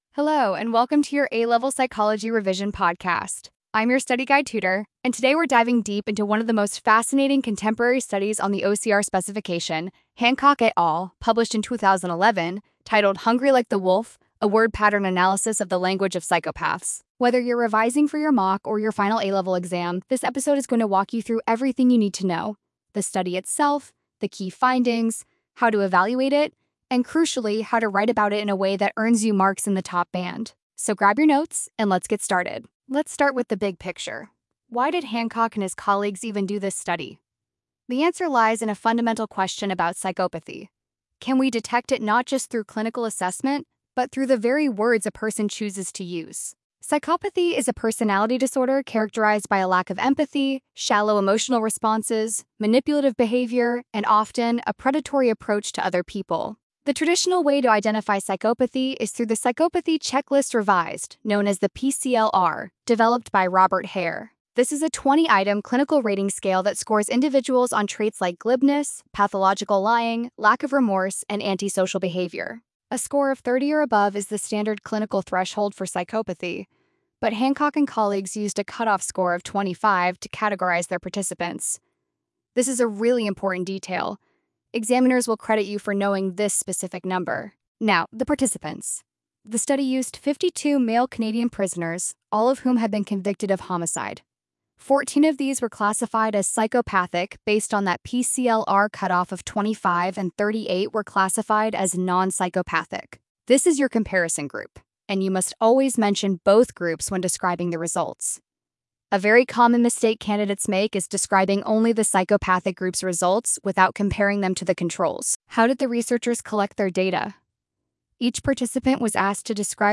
Revision podcast for Hancock et al. (2011).